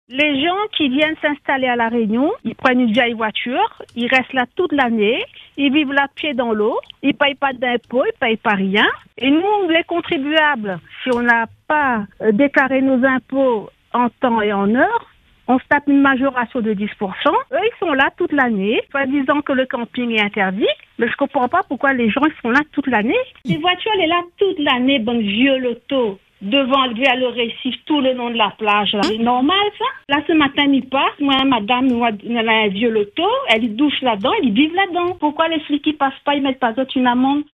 À La Saline-les-Bains, une habitante pousse un coup de colère face à une situation qu’elle juge préoccupante. Selon elle, des parkings de commerces sont régulièrement occupés par des personnes installées illégalement, parfois dans de vieux véhicules transformés en lieux de vie.